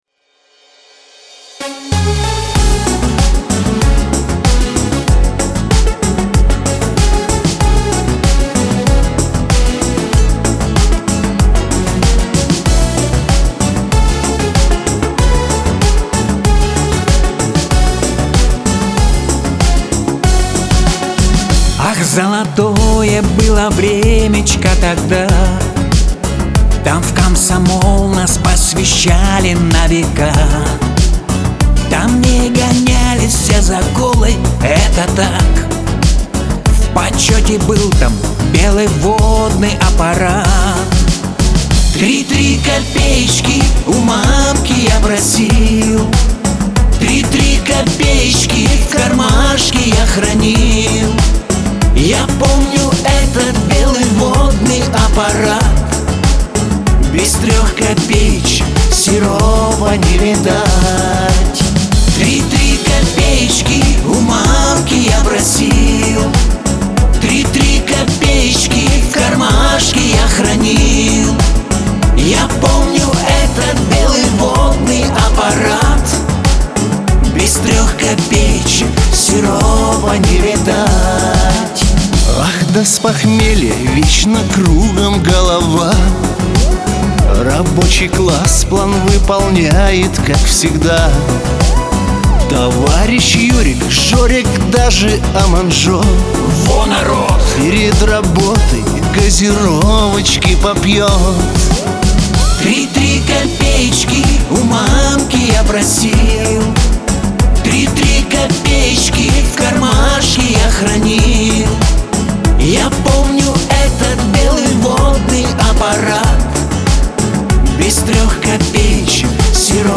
дуэт , Лирика , грусть
диско